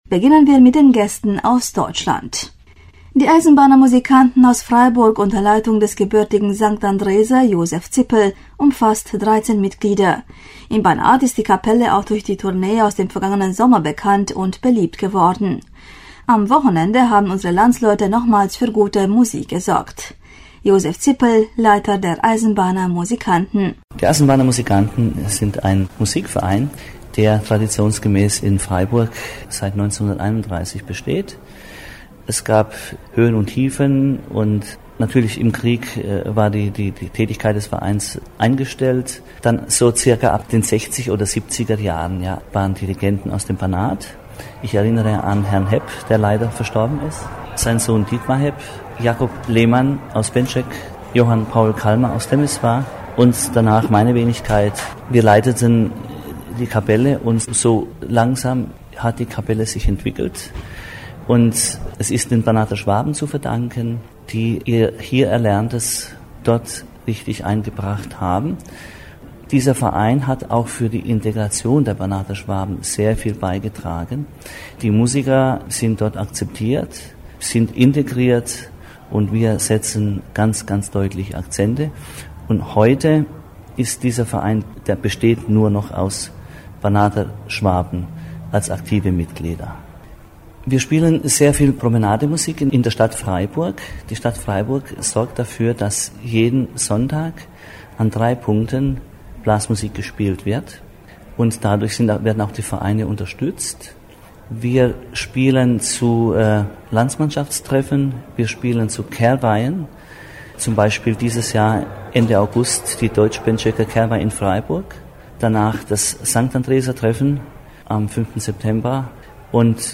Mit einigen der mitwirkenden Gruppenleiter sprach